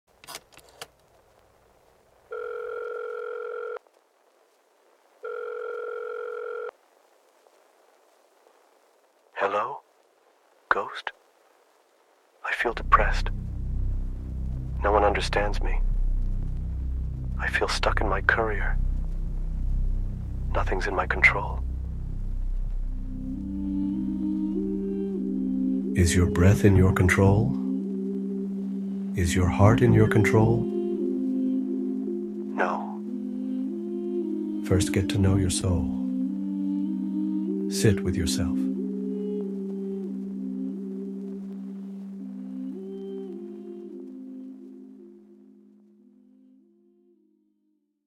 Punjabi Music Album